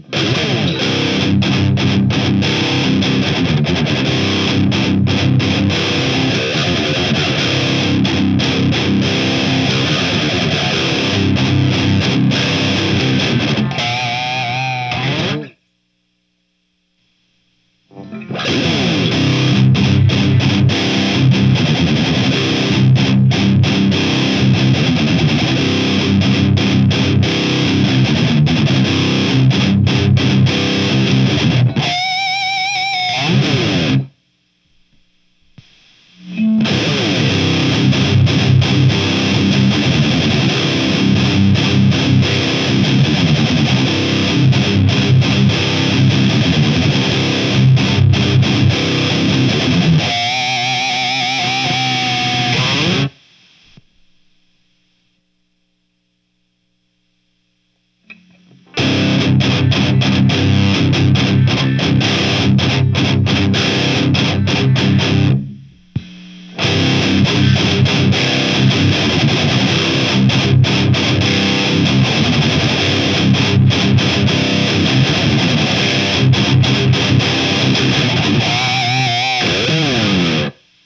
今回は実践？DSL100とベリンガーのブースター(BEHRINGERのPREAMP BOOSTER PB100)での比較。
EMG81です。
今回は、ゲイン6　トーンシフトオン
LEED1+ベリンガーとなってます。